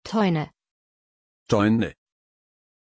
Pronuncia di Toine
pronunciation-toine-de.mp3